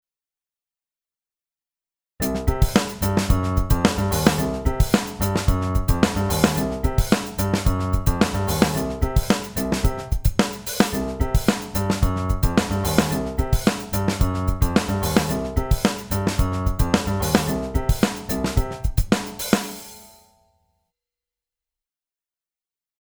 A Funk Bassline
Here is an example of a funky bassline. It consists of notes of the C blues scale.
Adding drums to this bassline gives the groove even more funkiness!
funk-bassline_1.mp3